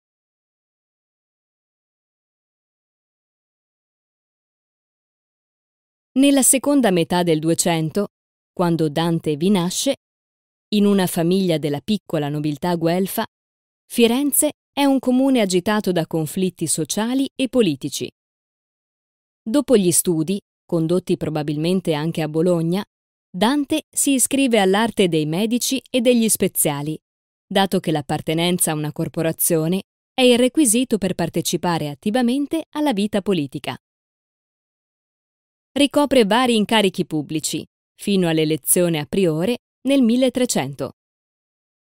Commento sonoro ZIP /wp-content/uploads/3-slide.mp3 DANTE ALIGHIERI